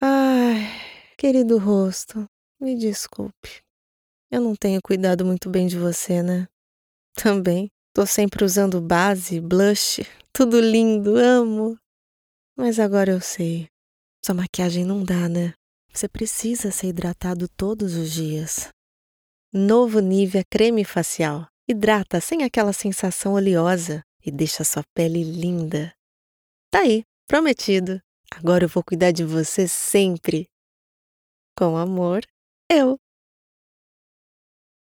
Sprechprobe: Sonstiges (Muttersprache):
My voice style is natural and conversational, with a neutral accent. My voice is very warm, versatile, conveys credibility, in addition to being jovial, expressive and extremely professional.